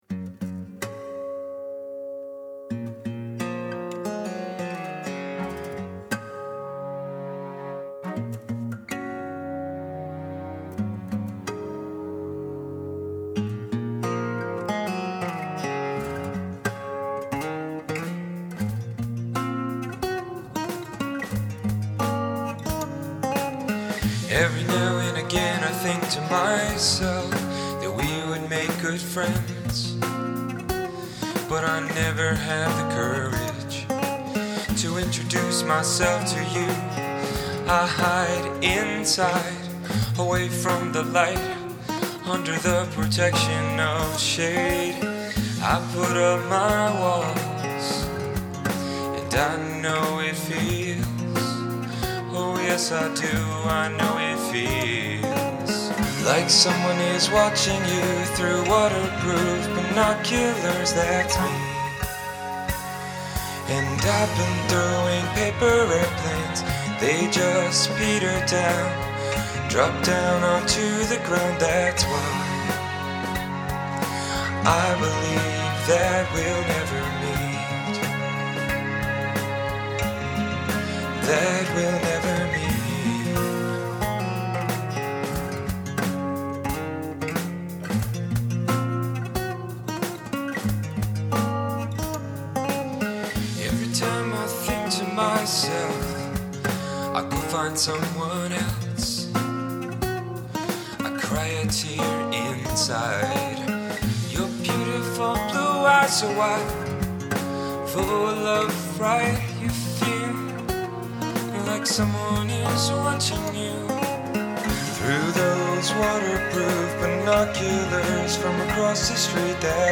Unplugged
December 30, 2009 | Drop D Tuning
Paper-Airplanes-Unpluged.mp3